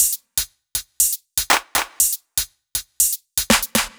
Index of /musicradar/french-house-chillout-samples/120bpm/Beats
FHC_BeatA_120-02_HatClap.wav